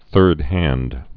(thûrdhănd)